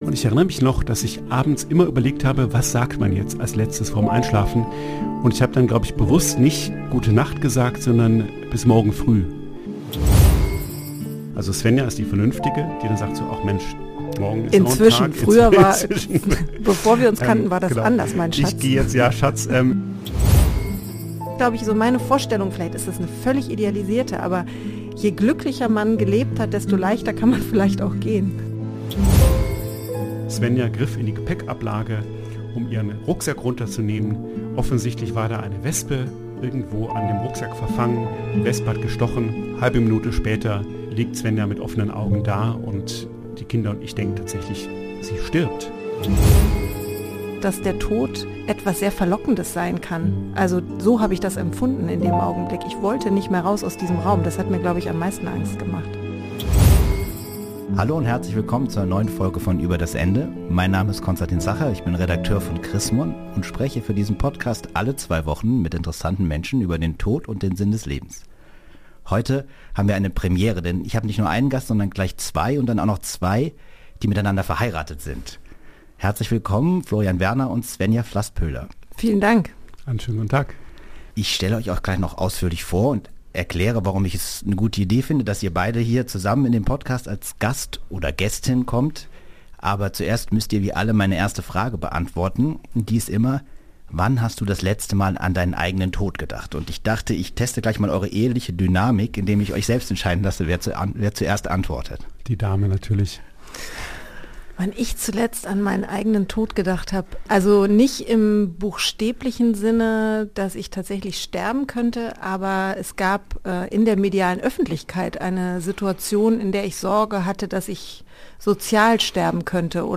In unserem Gespräch ging es um die Frage, wer zuerst sterben soll und warum das eine unmögliche Frage ist. Es ging um die Angst um das Leben der Kinder und darum, ob man den eigenen Tod planen kann und sollte. Es ist ein tiefsinniges und tiefgreifendes Gespräch geworden!